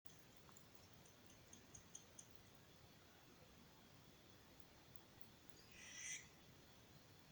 Suindara (Tyto furcata)
Nome em Inglês: American Barn Owl
Província / Departamento: Santa Fe
Condição: Selvagem
Certeza: Gravado Vocal
Lechuza-campanario-1.mp3